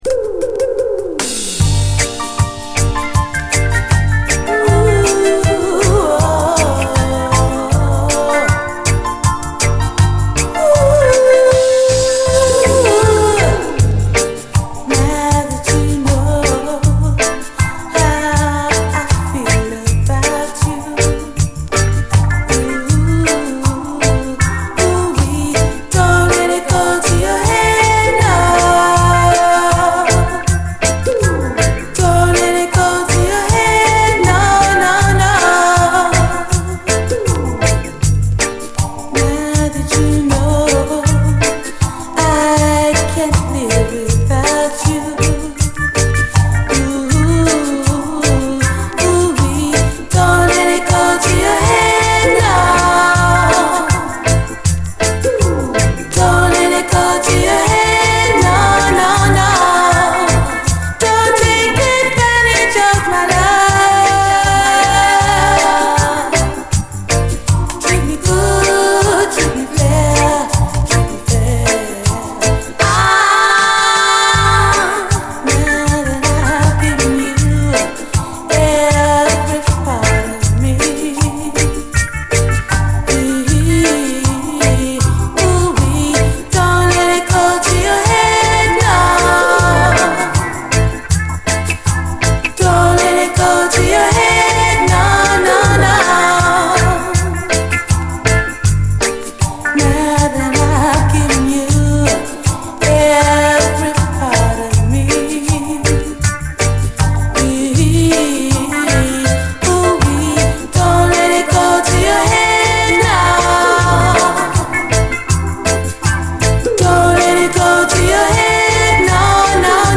上質のUKラヴァーズ〜UKルーツ・サウンドで魅了します！
なんと滑らかなサウンド！
スウィート極まるラヴァーズ